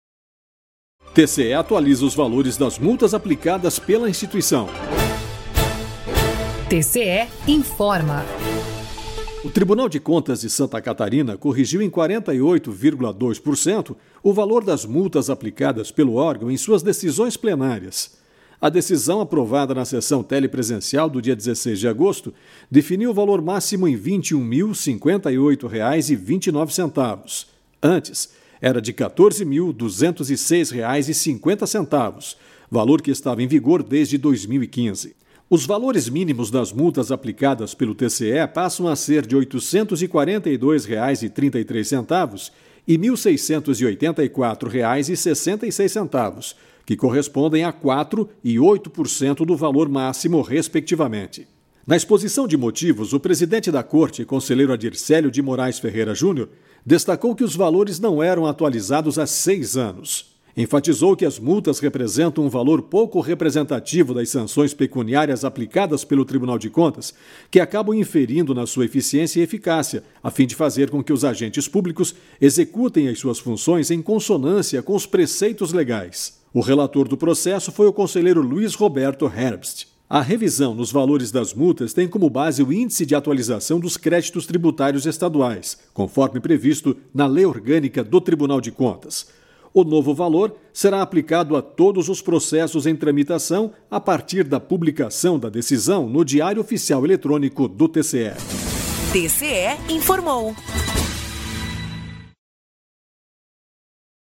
VINHETA TCE INFORMA
VINHETA TCE INFORMOU